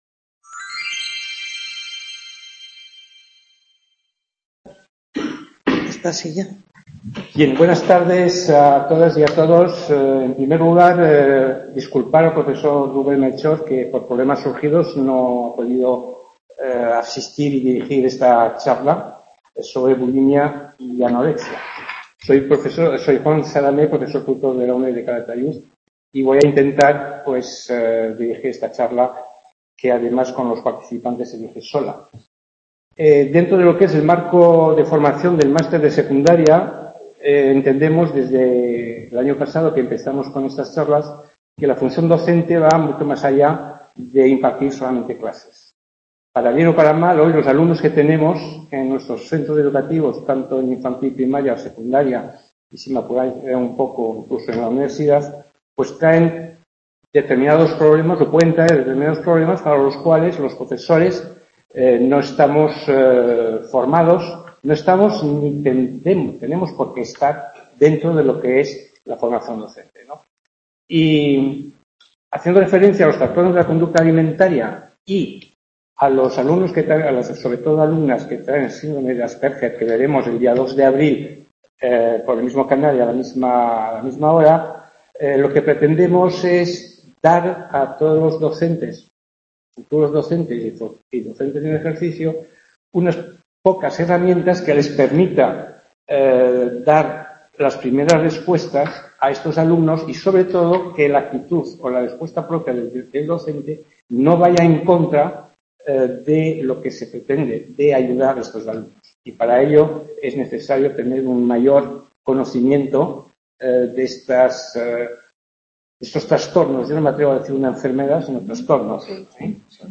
19-03-19 Charla bulimia y anorexia | Repositorio Digital
Charla sobre bulimia y anorexia, centro asociado Calatayud.